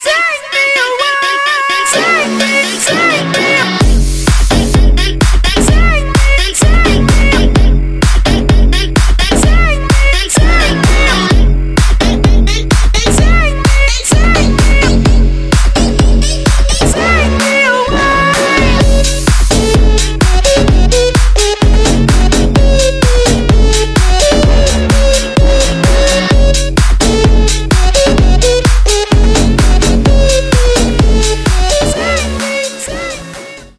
• Качество: 128, Stereo
танцевальная